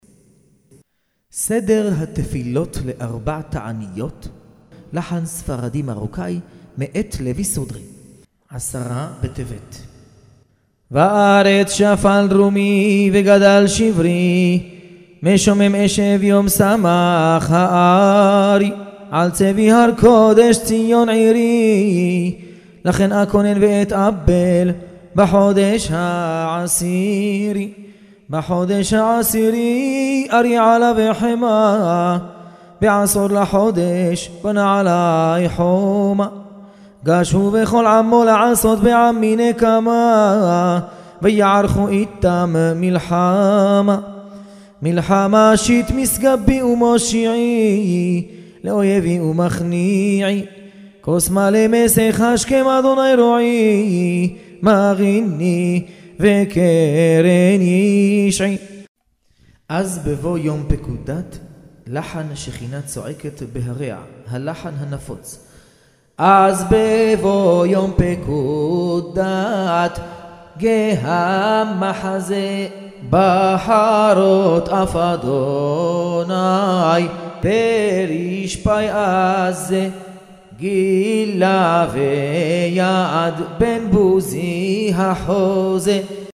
התפילה המוקלטת
סליחות לארבע תעניות ותיקון חצות נוסח מרוקו (3 שעות)
63_recorded_prayer_selichot_4_taaniot_morocco.mp3